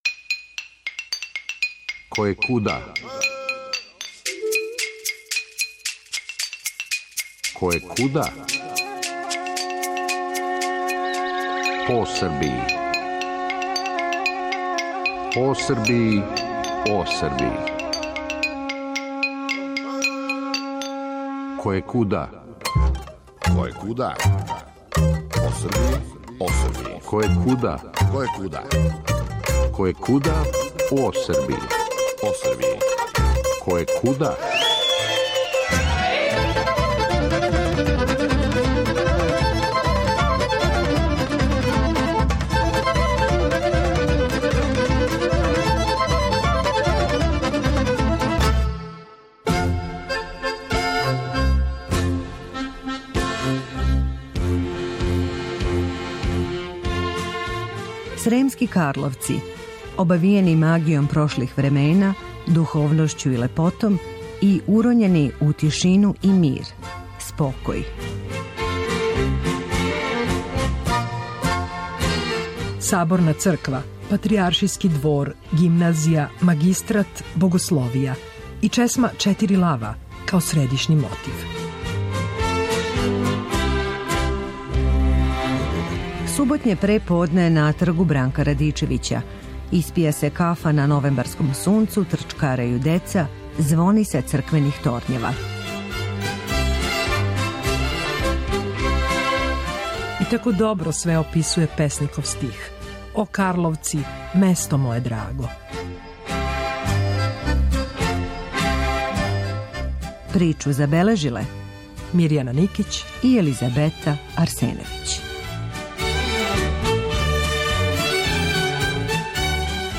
Суботње преподне на Тргу Бранка Радичевића - испија се кафа на новембарском сунцу, трчкарају деца, звони са црквених торњева...Саборна црква, Патријаршијски двор, Гимназија, Магистрат, Богословија - и чесма Четири лава, као средишњи мотив.